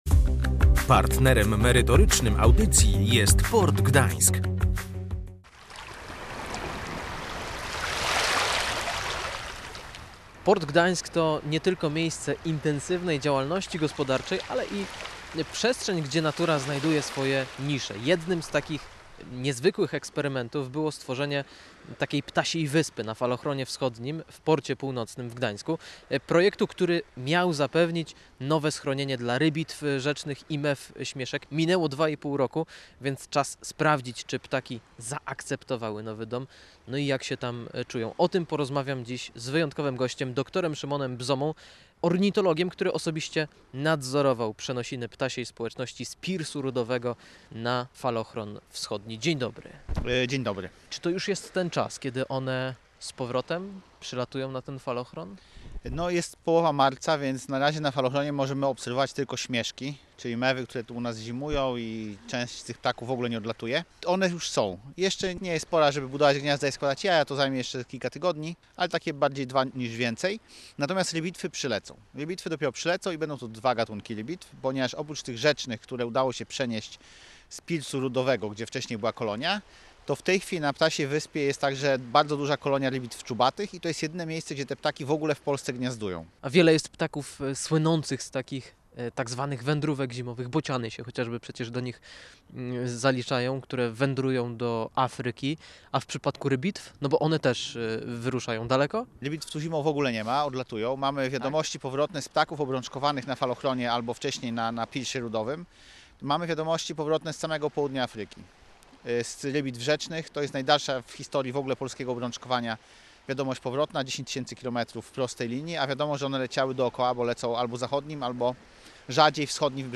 Posłuchaj audycji z podróży po portowych klimatach: